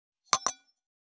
289皿が当たる音,皿の音,台所音,皿を重ねる,カチャ,ガチャン,カタッ,コトン,ガシャーン,カラン,カタカタ,チーン,カツン,カチャカチャ,
コップ効果音厨房/台所/レストラン/kitchen食器